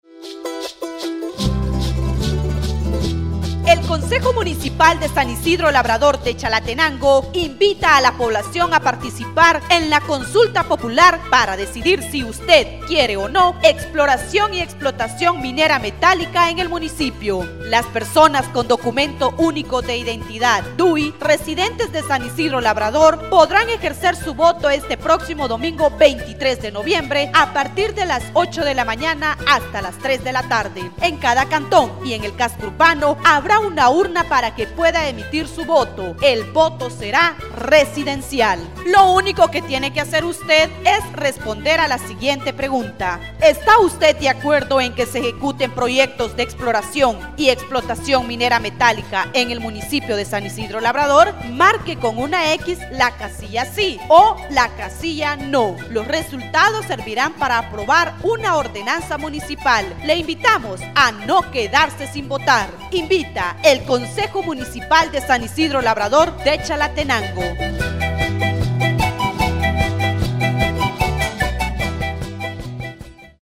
Cuña de 2da Consulta Popular San Isidro Labrador